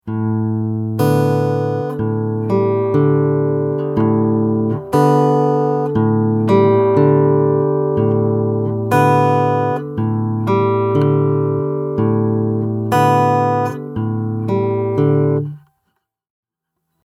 All of the rhythms in these examples have been kept fairly simple, and in 4/4 time.
Examples 9 and 10 are what example 1 would look like once it is moved to the 5th-string root, as obviously you’ll want to play more than just 6th string rooted shapes.
Alternating Bass Fingerstyle 9
audio for this alternating bass fingerstyle pattern.